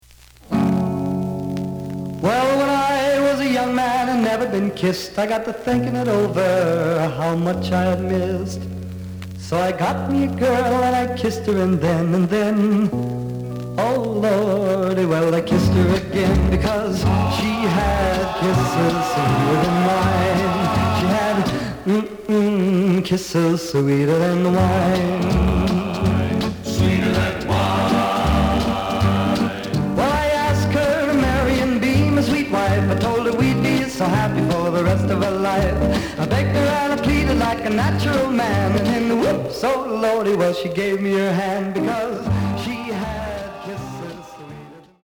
The listen sample is recorded from the actual item.
●Genre: Rhythm And Blues / Rock 'n' Roll
Some noise on both sides.